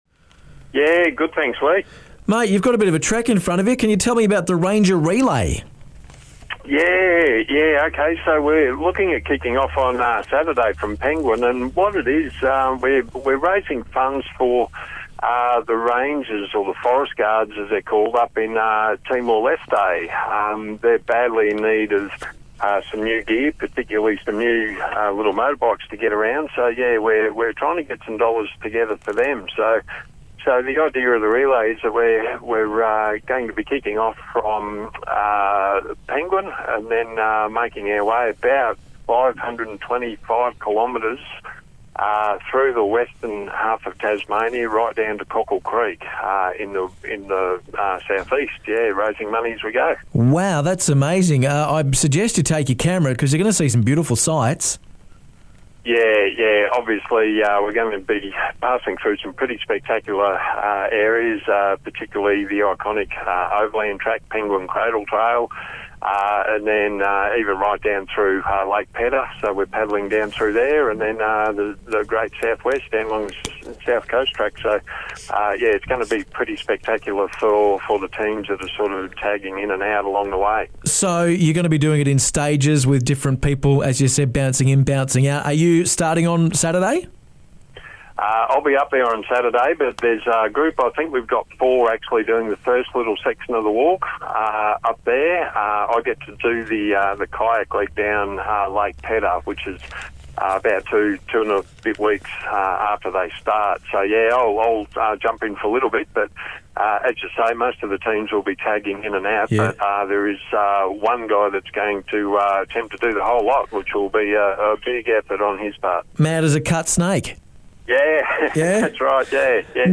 calls in to The Home Run ahead of launch day in Penguin this Saturday.